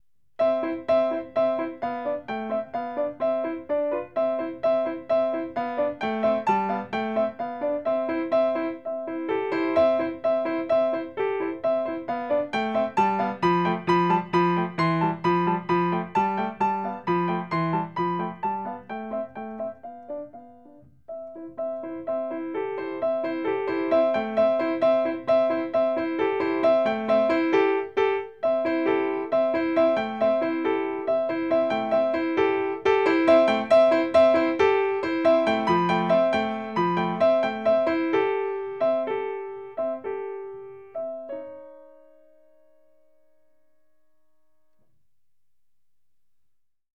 Solos piano